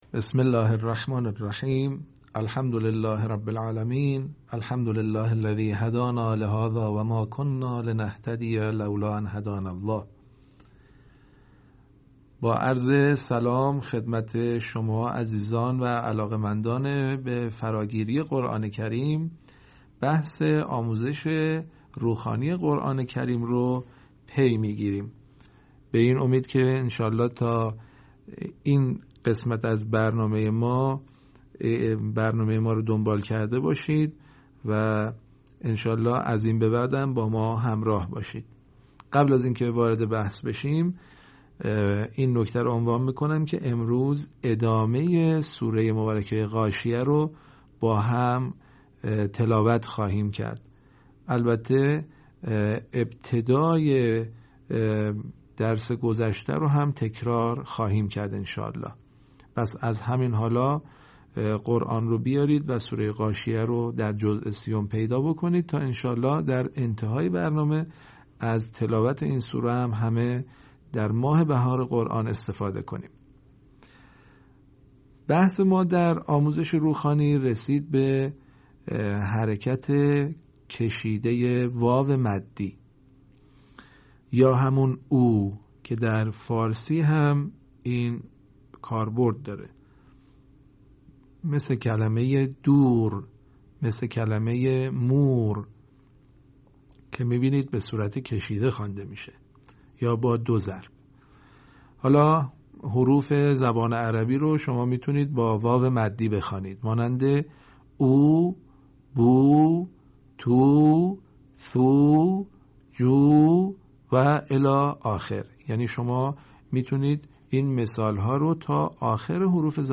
صوت | آموزش حرکات کشیده و واو مدی در روخوانی قرآن کریم